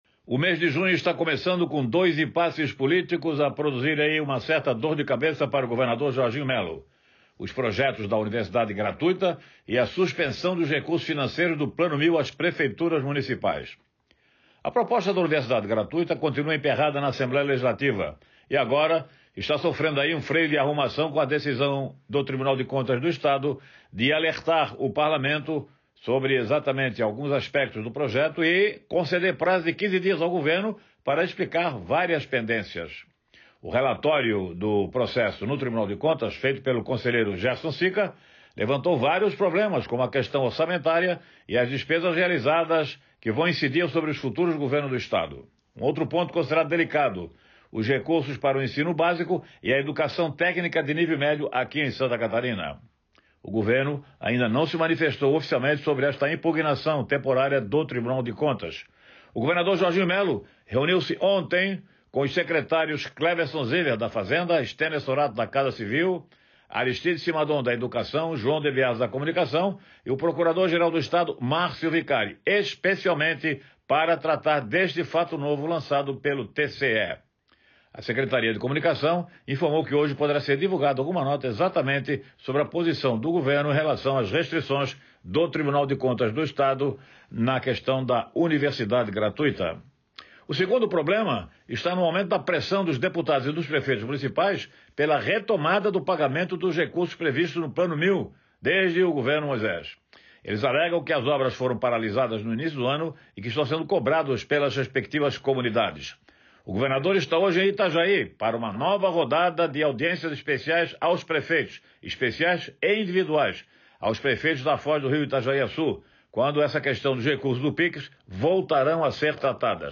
Jornalista comenta que o Governo precisará enfrentar dois impasses políticos ao longo do mês de junho que podem causar incômodos na atual gestão